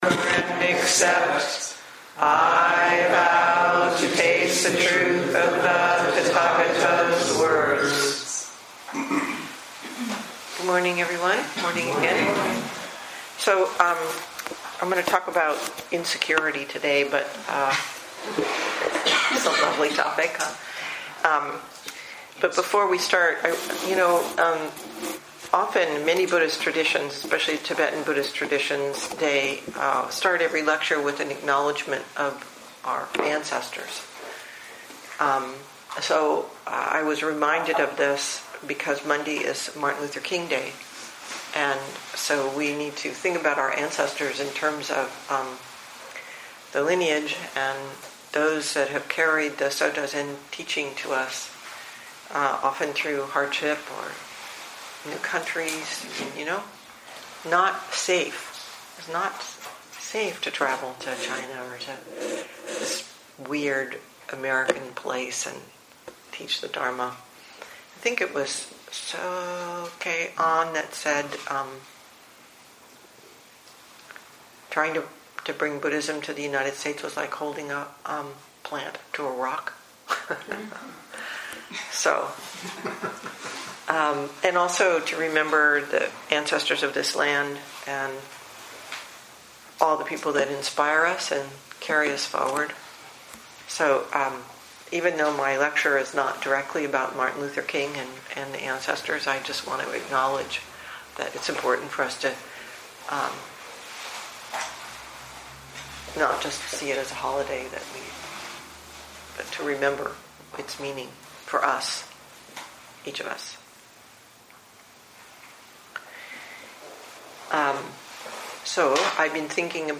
Dharma Talks